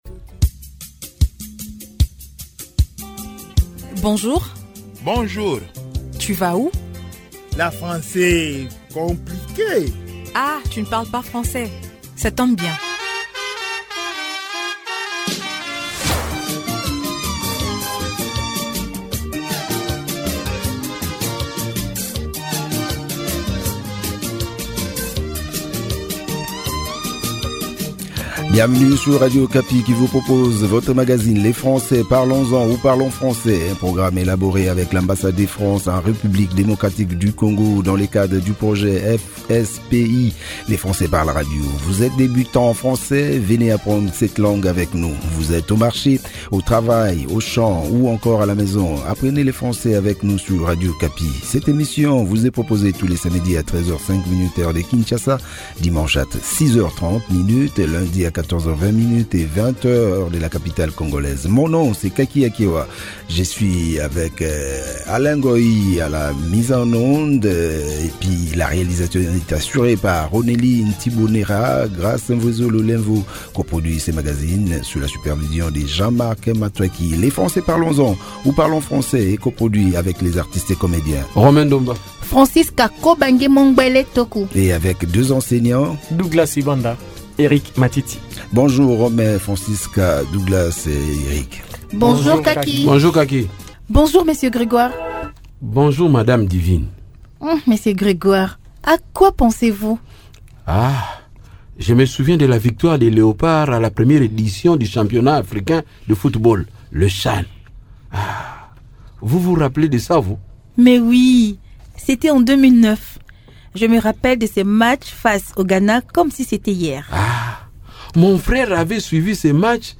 avec la participation de deux enseignants et de deux comédiens.